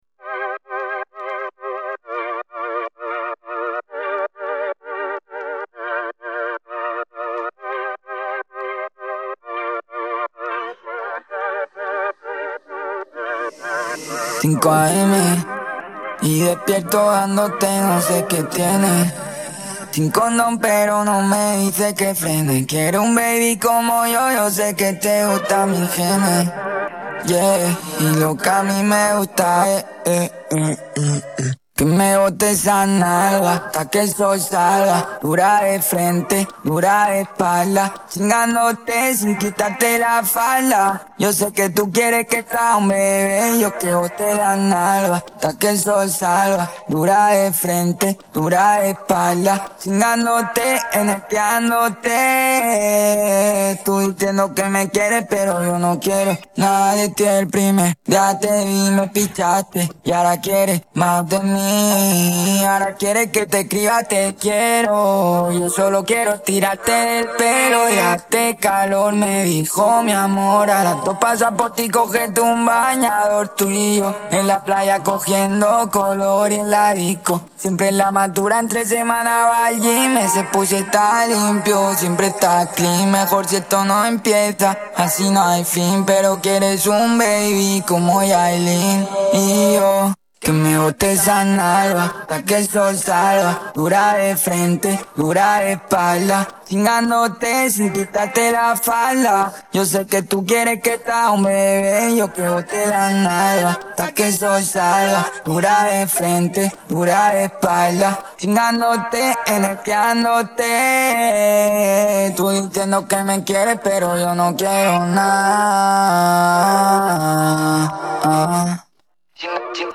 Partie vocale